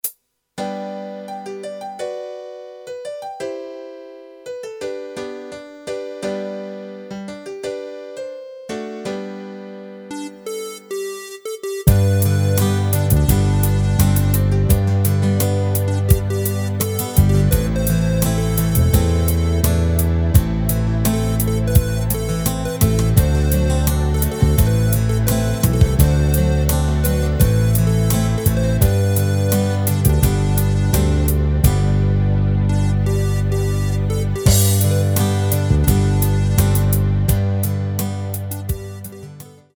Rubrika: Pop, rock, beat
Karaoke